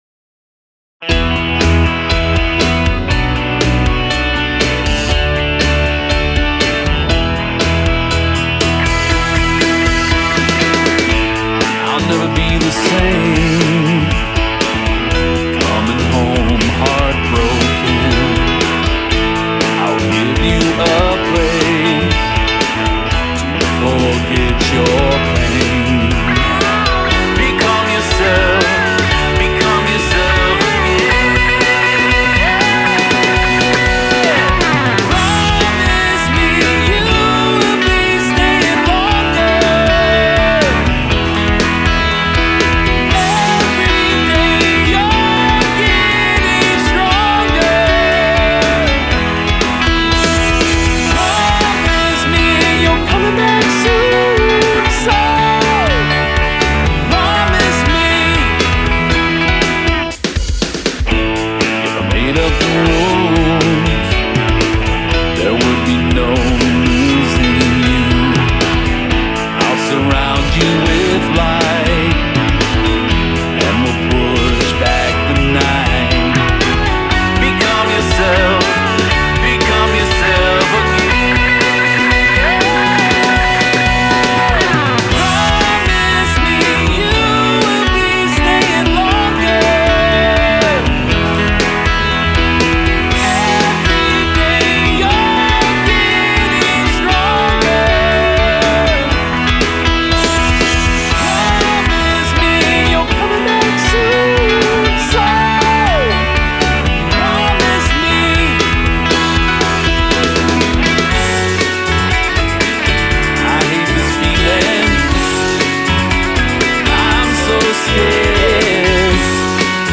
Rock Pop Rockabilly Reggae Ska Country Rock